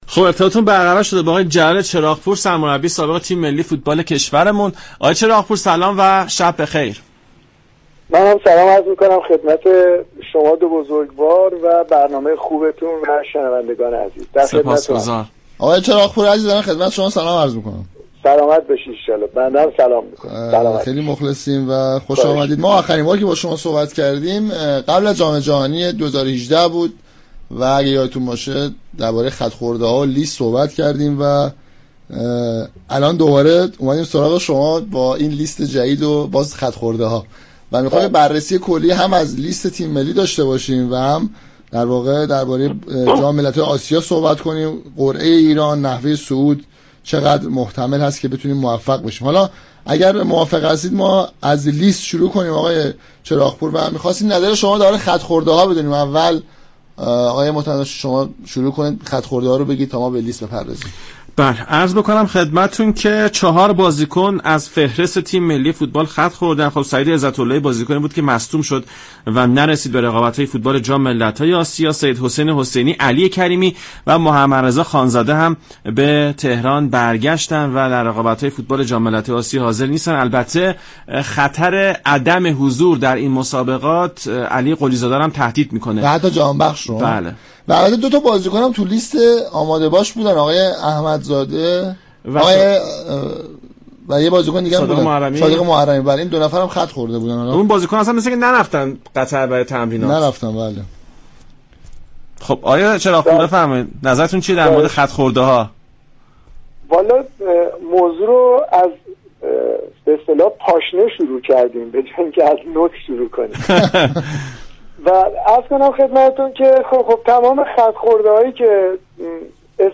جلال چراغ پور سرمربی سابق تیم ملی ایران در گفت و گو با رادیو ایران گفت.